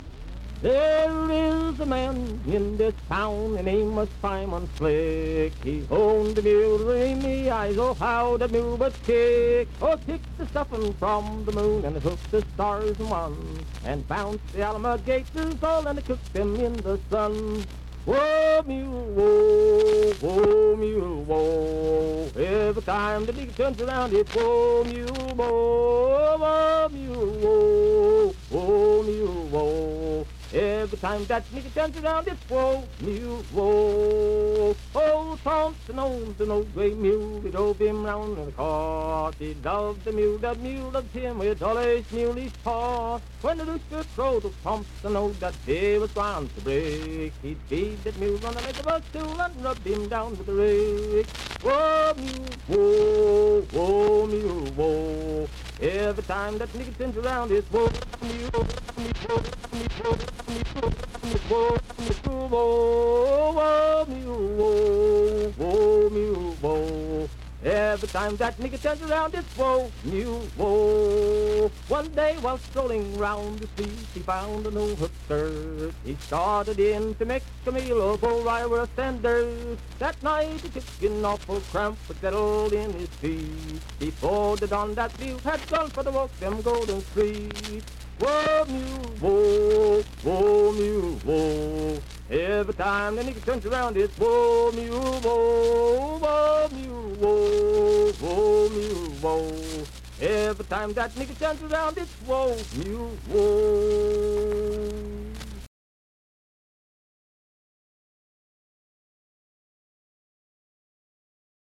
Unaccompanied vocal performance
Verse-refrain 4d(4) &Rd(4).
Minstrel, Blackface, and African-American Songs
Voice (sung)
Vienna (W. Va.), Wood County (W. Va.)